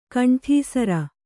♪ kaṇṭhīsara